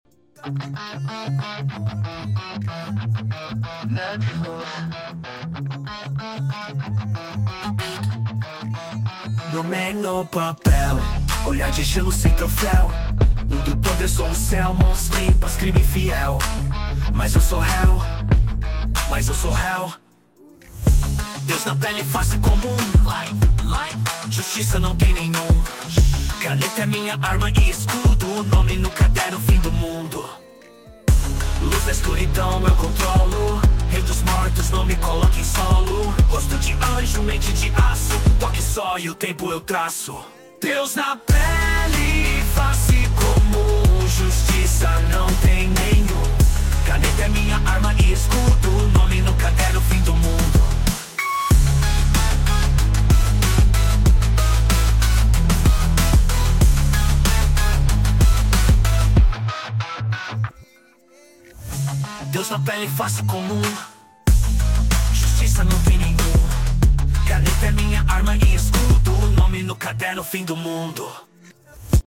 Musica geek, trap